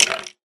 skeletonhurt3.ogg